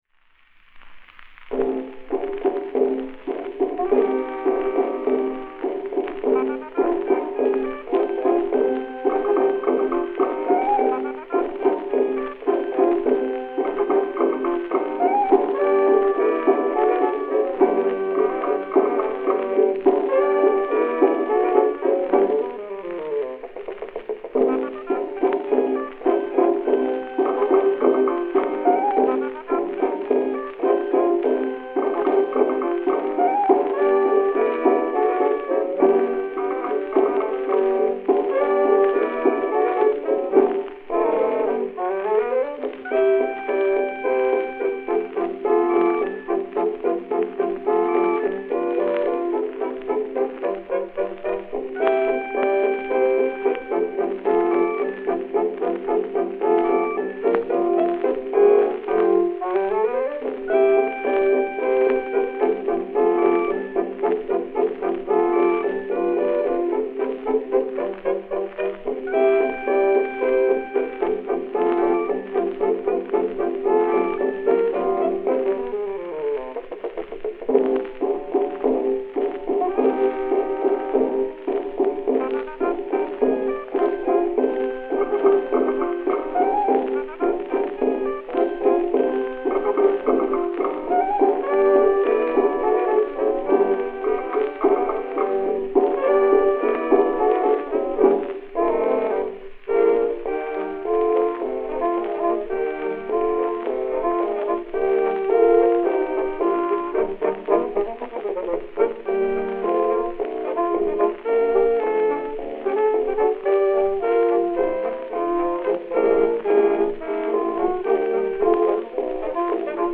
Fox-trot